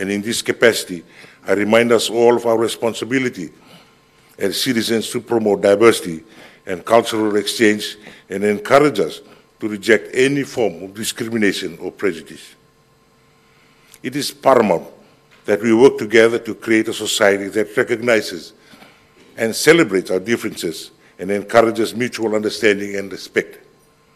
President Ratu Wiliame Katonivere at the Girmit Day International Conference
In his opening address at the Girmit Day International Conference this morning, Ratu Wiliame highlighted the lack of emphasis on Fiji’s history within the primary and secondary school curriculum.